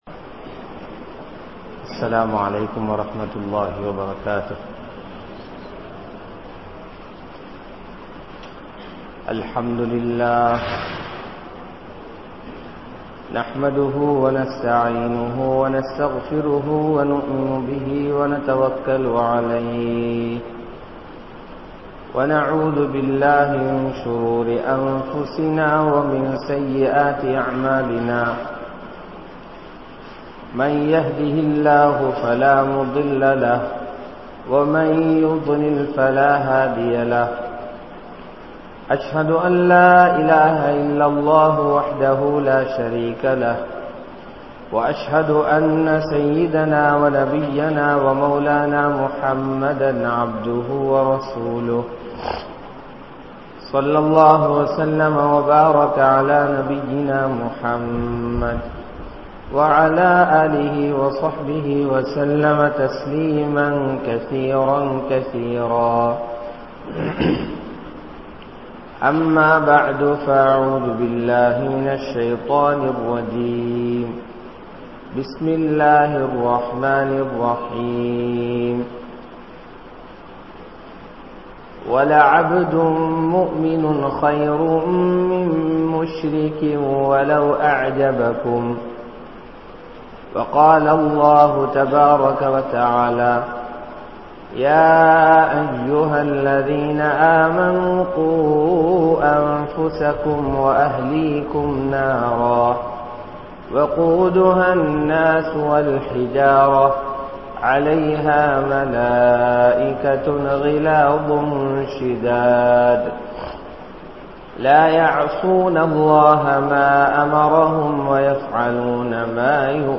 Youth | Audio Bayans | All Ceylon Muslim Youth Community | Addalaichenai